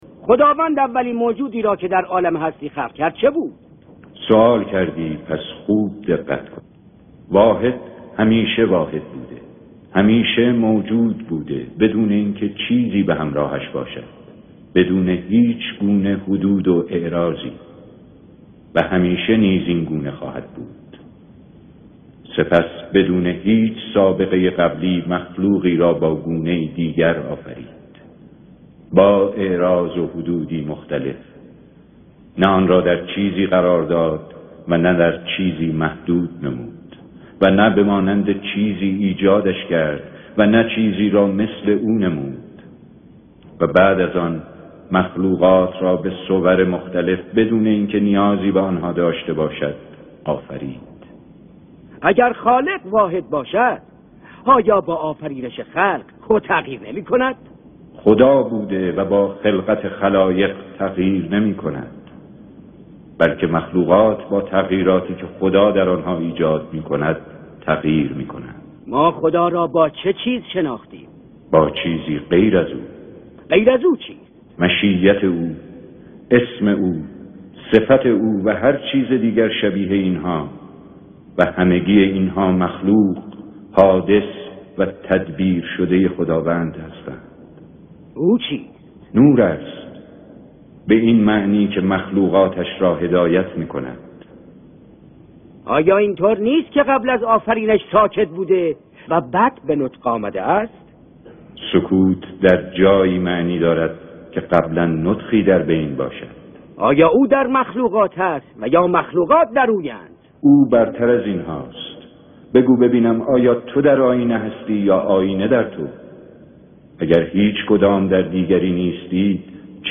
این فایل پیوست شده مصاحبه ی بین امام رضا و عمران صابی هستش که نیاز به راهنمایی داره ، لطفا به زبان ساده و گویا پاسخ دهید با تشکر .
مناظره